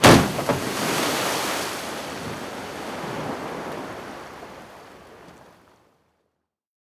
transport
Car Falling Into Water 2